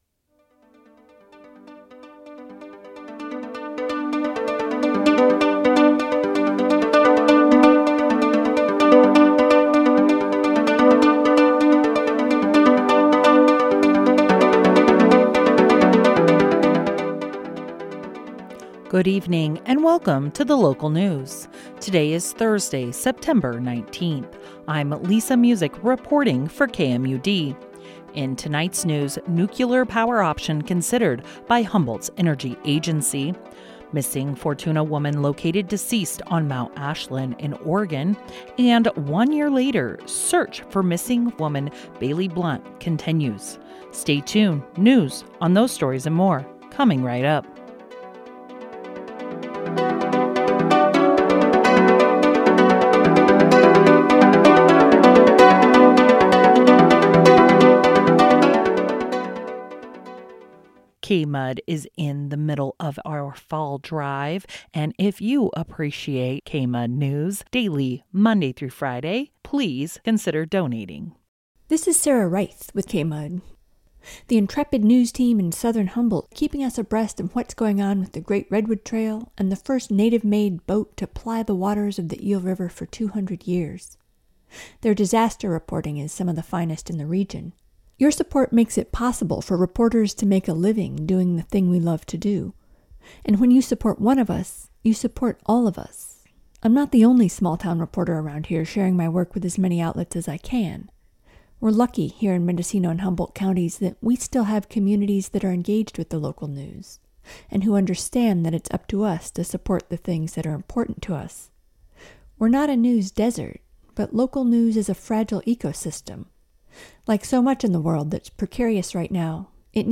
Local news.